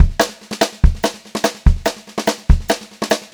144SPBEAT2-R.wav